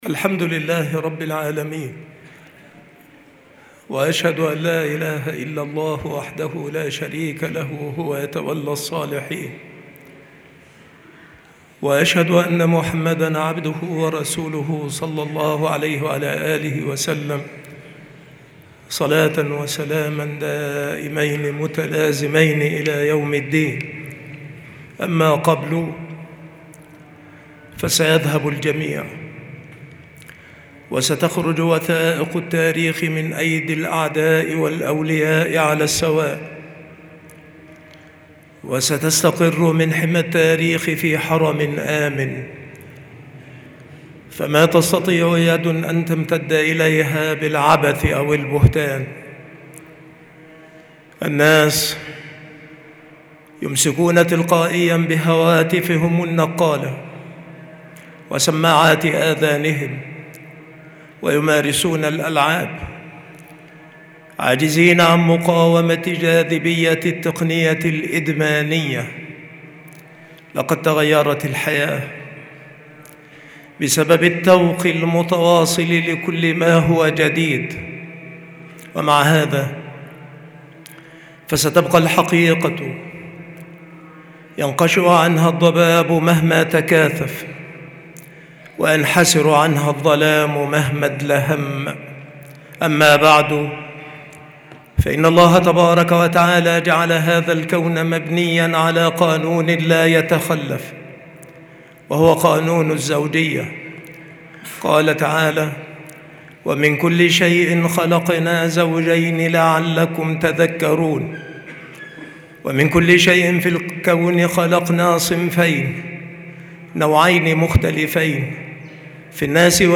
خطر المخدرات جديد خطبة الجمعة بعنوان